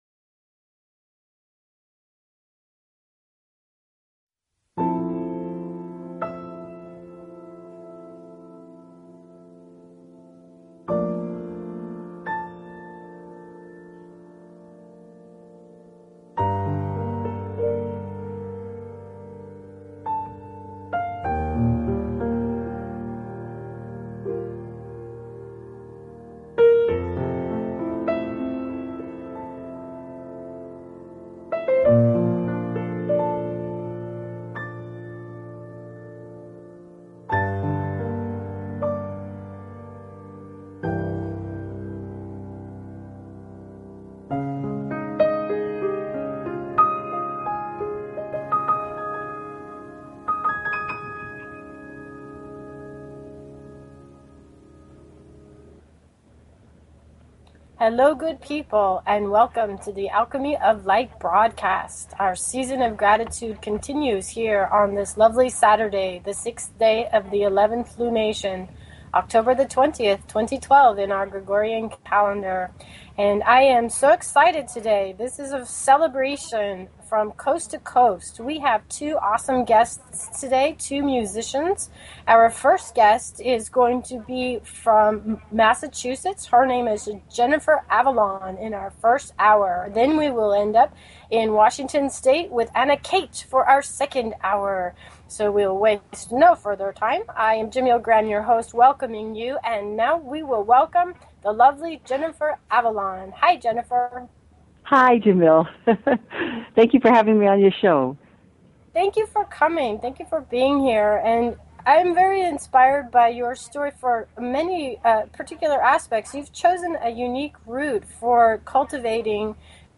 The Alchemy of Light Broadcast is a celebration and exploration of Consciousness. This show integrates the available progressive social and natural science based genius alongside the more intimate genius of individuals sharing their stories.